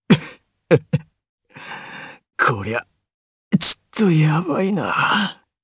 Kibera-Vox_Dead_jp.wav